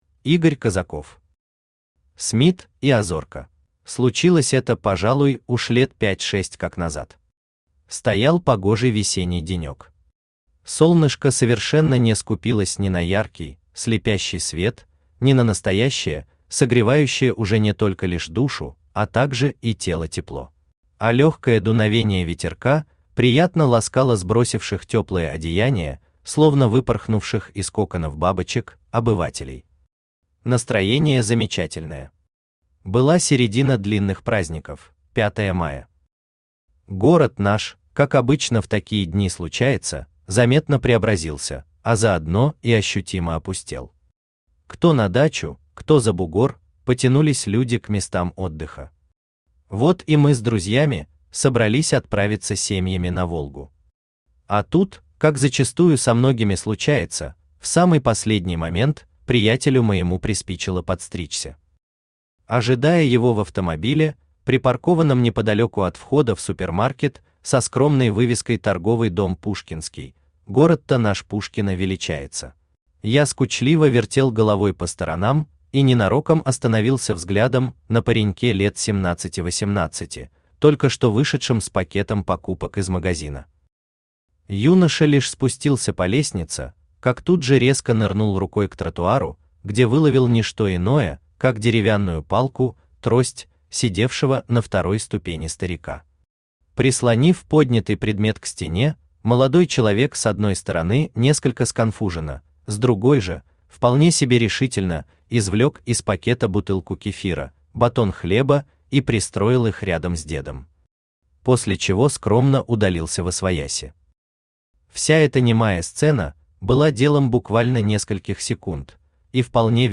Аудиокнига Смит и Азорка | Библиотека аудиокниг
Aудиокнига Смит и Азорка Автор Игорь Козаков Читает аудиокнигу Авточтец ЛитРес.